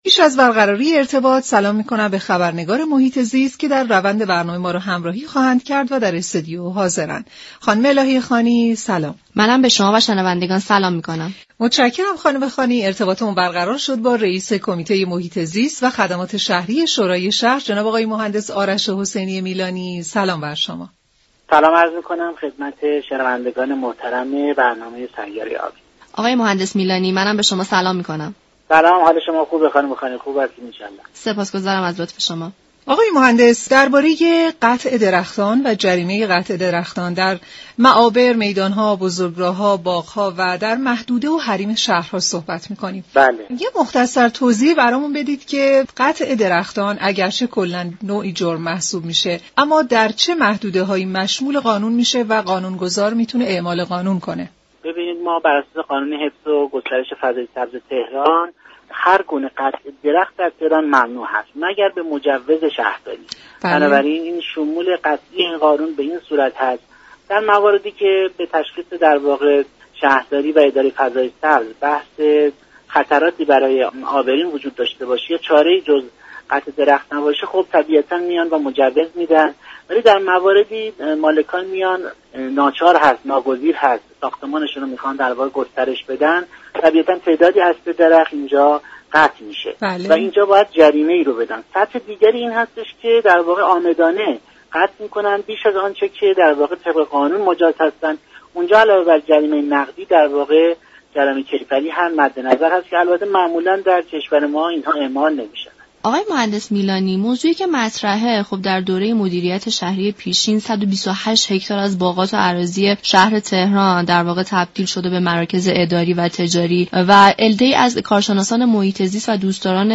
دریافت فایل به گزارش شبكه رادیویی ایران، آرش حسینی میلانی رییس كمیته محیط زیست و خدمات شهری شورای شهر تهران در گفت و گو با برنامه سیاره آبی درباره قطع درختان در معابر و محدوده شهرها گفت: شواری شهر تهران بر اساس قانون حفظ و گسترش فضای سبز تهران، قطع هرگونه درخت در معابر عمومی را ممنوع اعلام كرده است.